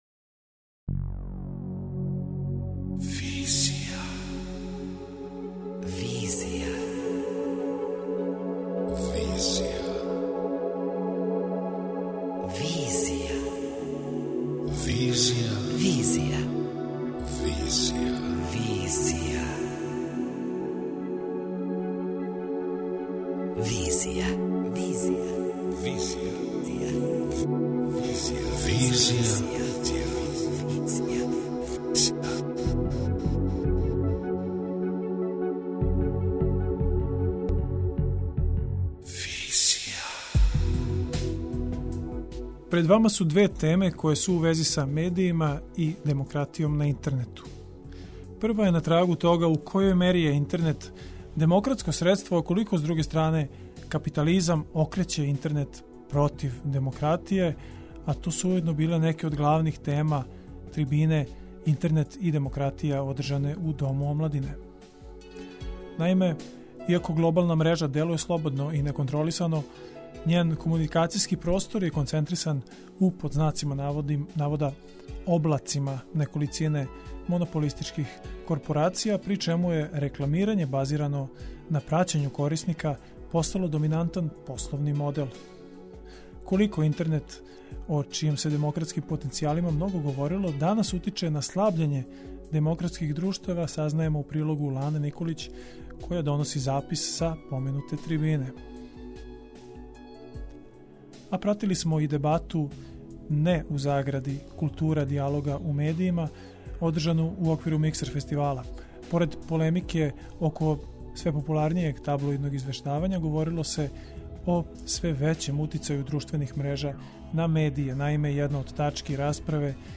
Пратили смо и дебату ''(Не)култура дијалога у медијима'' одржану у оквиру Миксер фестивала. Поред полемике око све популарнијег таблиодног извештавања, говорило се о све већем утицају друштвених мрежа на медије.